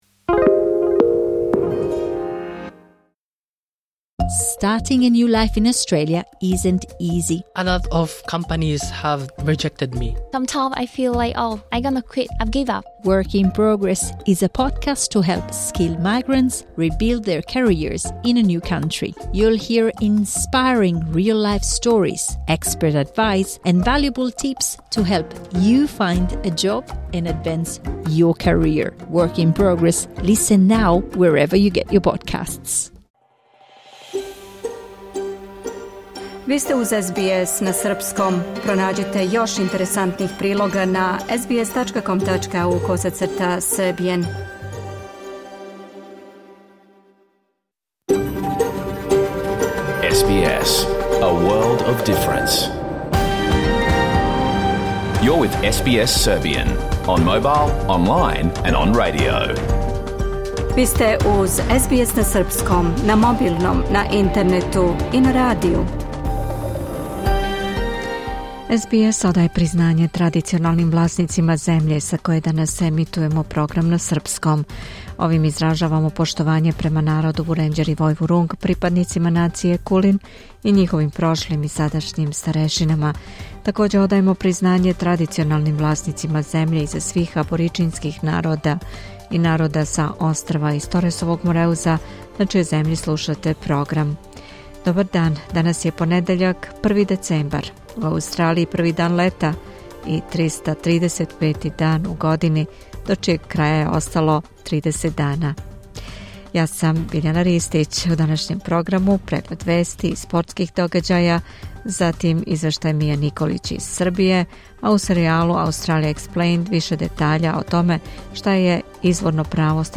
Програм емитован уживо 1. децембра 2025. године